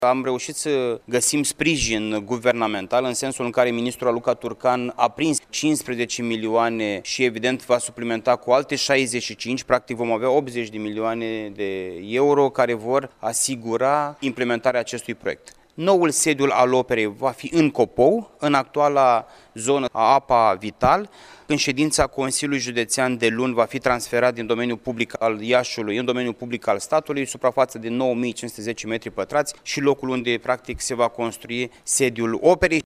Pentru realizarea acestui obiectiv, peste 9.500 de metri pătrați de teren ar urma să fie transferați din domeniul public al Iașului în cel al statului, a precizat președintele Consiliului Județean Iași, Costel Alexe.